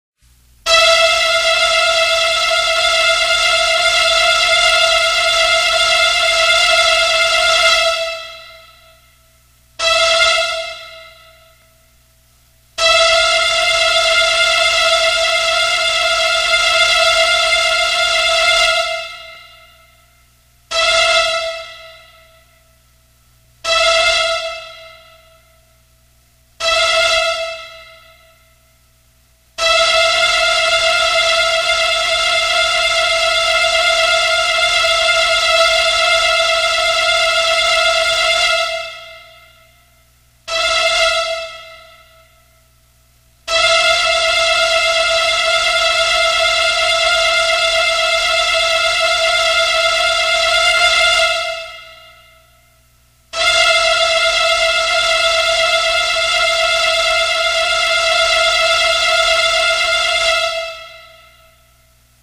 • Ejercicio: SONIDOS LARGOS Y CORTOS: Con la ayuda de los padres o tutores los alumnos estarán identificando los sonidos largos y cortos en el audio y escribirán en el orden de aparición si el sonido es largo la letra L o la palabra LARGO y si es corto C o la palabra CORTO.
• RESPUESTA:1) L; 2) C; 3) L; 4) C; 5) C; 6) C; 7) L; 8) C; 9) L; 10) L
Ejercicio-de-sonidos-cortos-y-largos.mp3